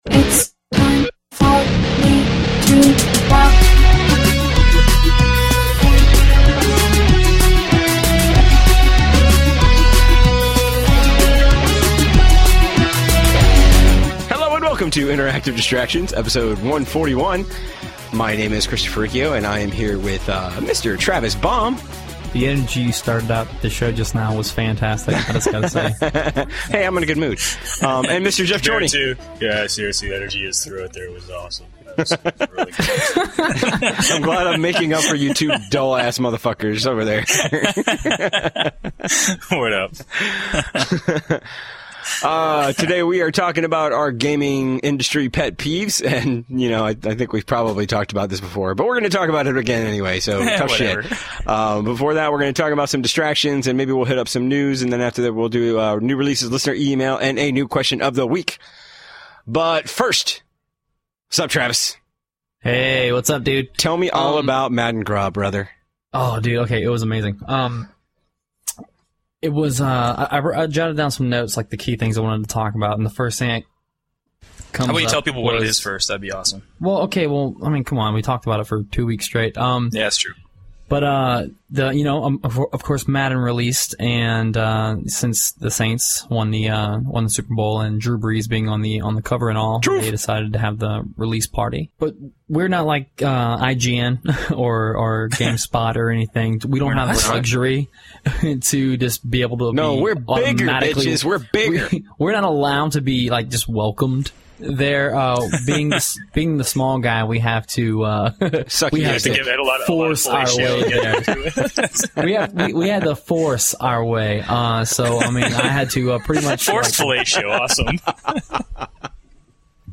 Regular crew plus a member of Sega’s community management team during the feature.